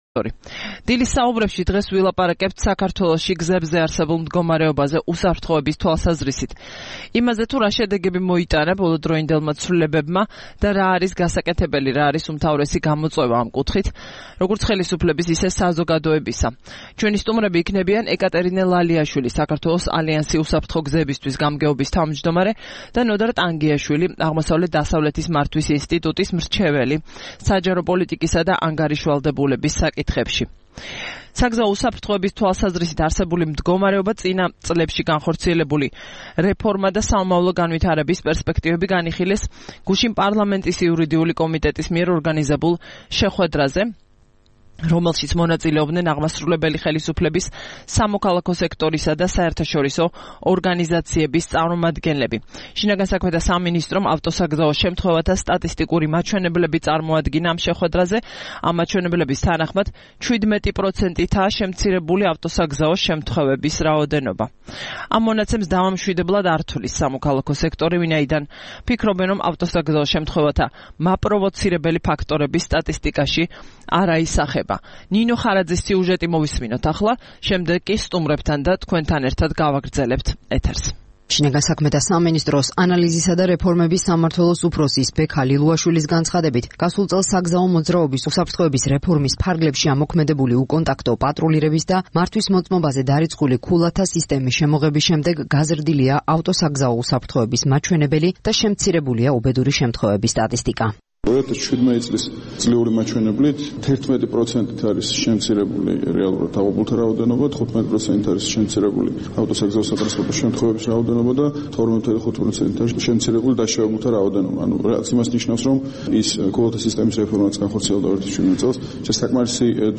რადიო თავისუფლების "დილის საუბრების" სტუმრები იყვნენ: